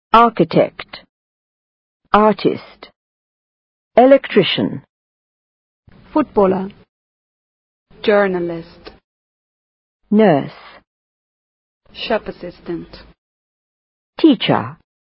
Este archivo de sonido contiene la pronunciación de algunas palabras en inglés que sirven para denominar oficios, profesiones y ocupaciones.